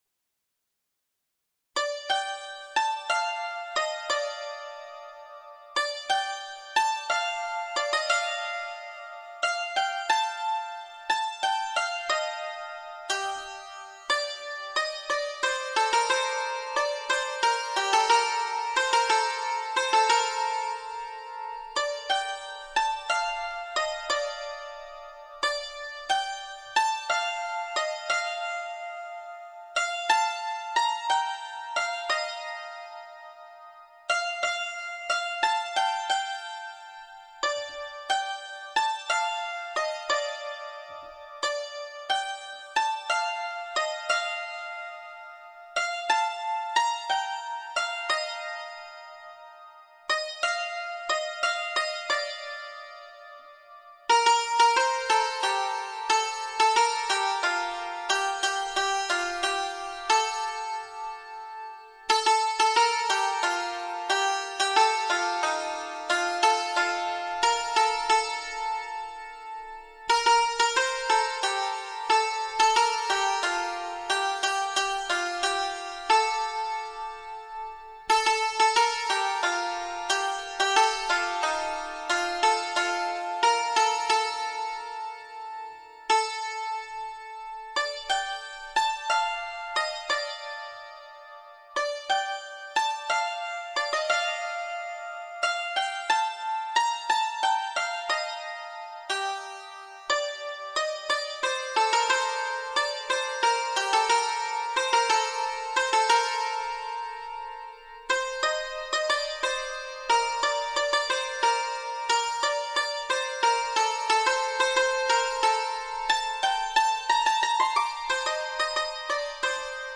سطح : ساده